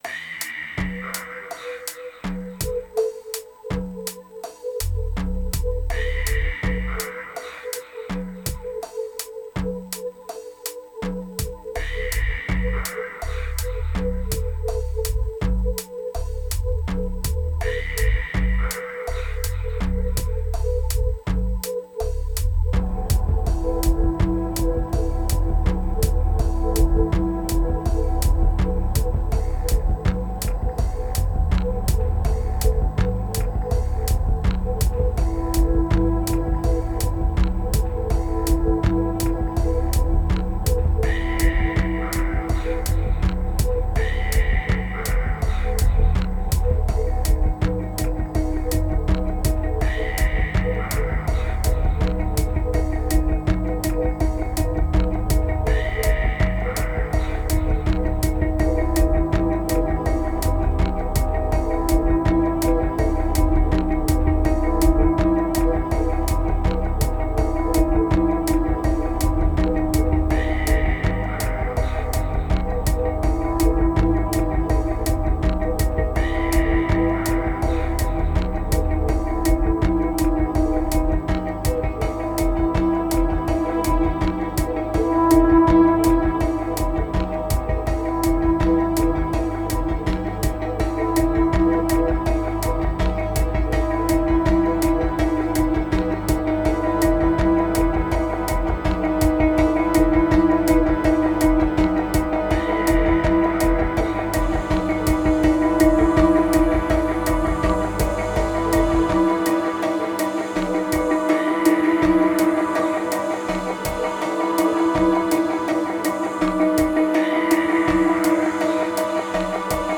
532📈 - 90%🤔 - 82BPM🔊 - 2021-11-26📅 - 469🌟